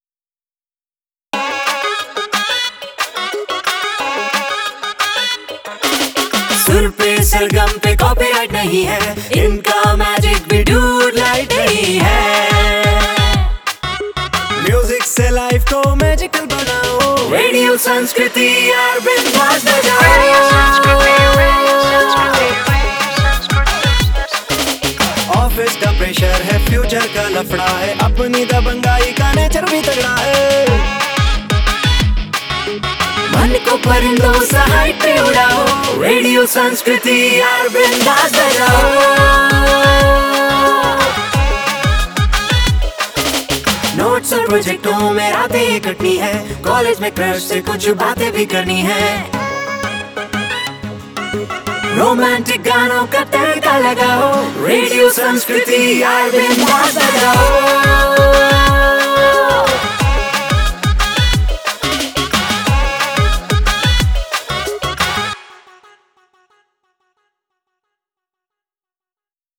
Jingle 2